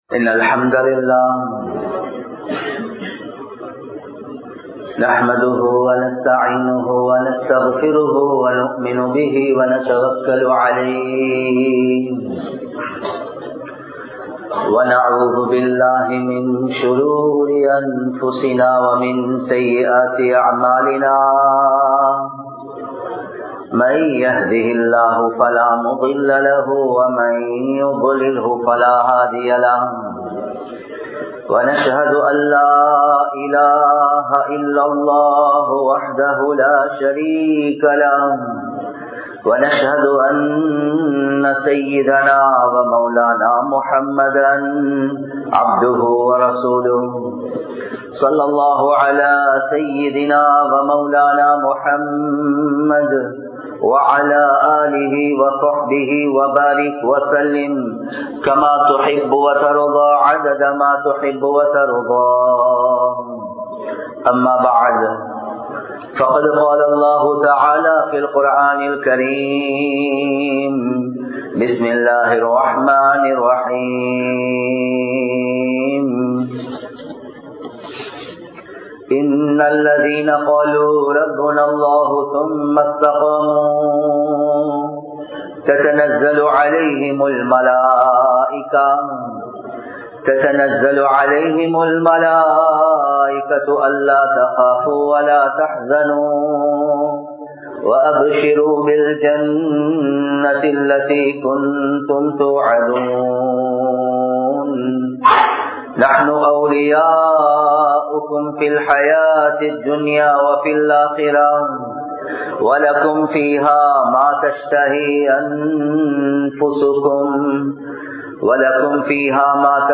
Islamiya Valifarhalin Mun Mathirihal (இஸ்லாமிய வாலிபர்களின் முன்மாதிரிகள்) | Audio Bayans | All Ceylon Muslim Youth Community | Addalaichenai
Dehiwela, Muhideen (Markaz) Jumua Masjith